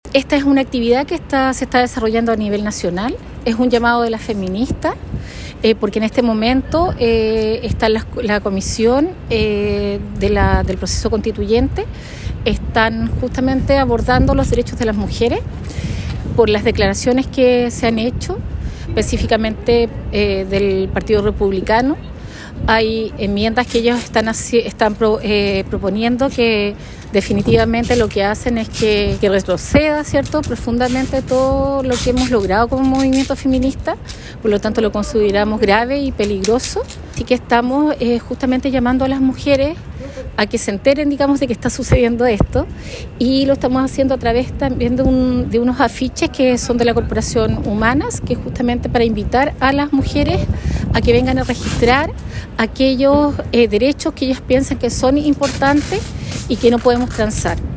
actualidad Entrevista Local